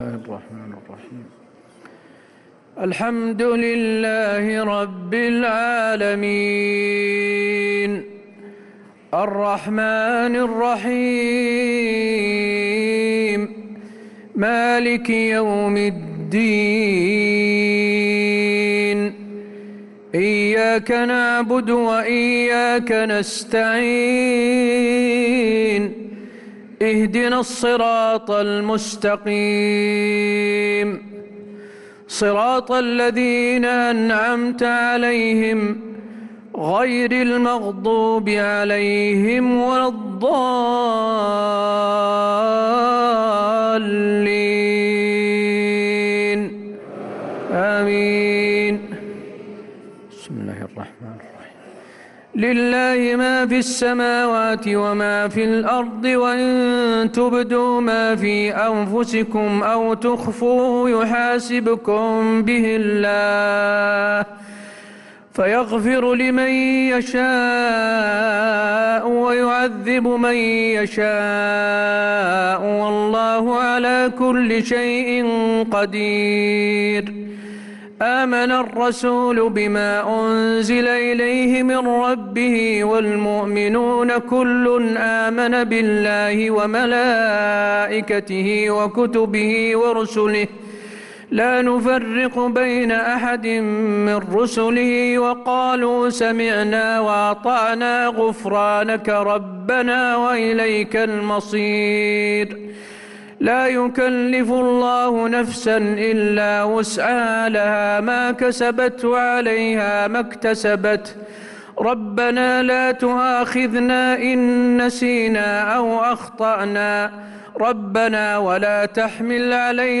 صلاة العشاء للقارئ حسين آل الشيخ 23 رمضان 1445 هـ